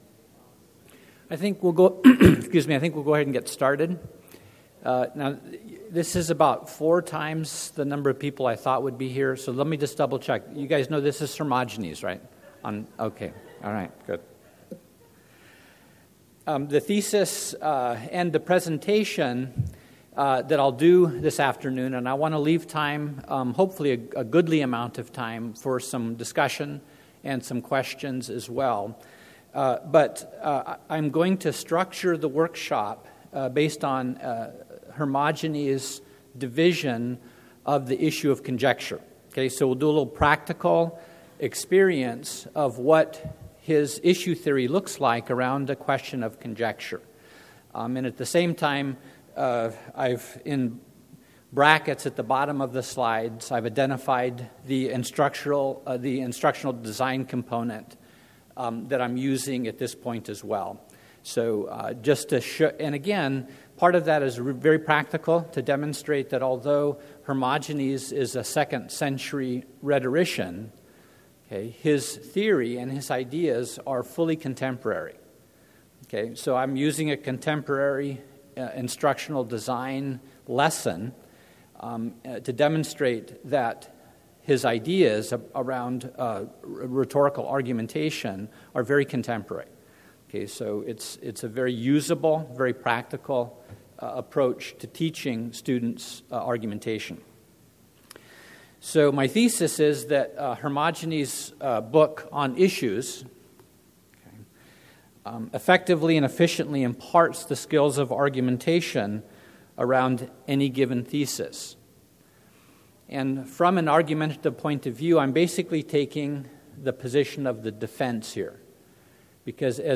2012 Workshop Talk | 0:43:18 | 7-12, Rhetoric & Composition